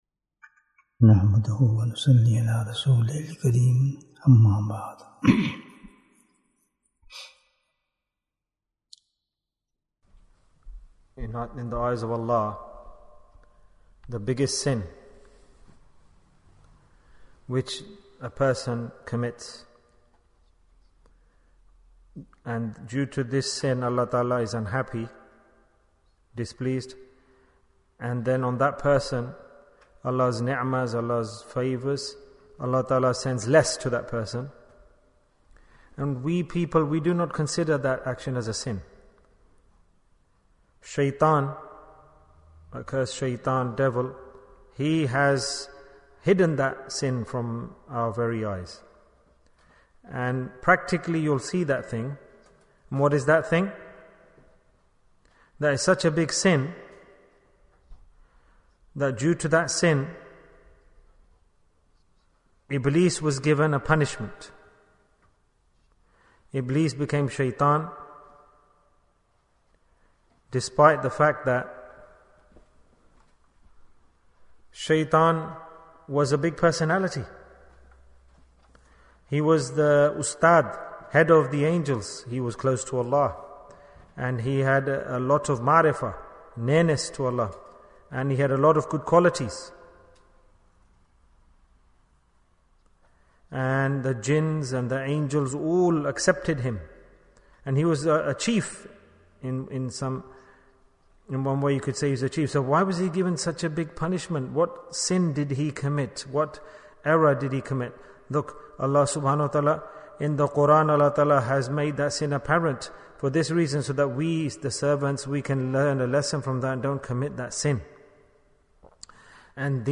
What is the Big Sin? Bayan, 19 minutes3rd January, 2023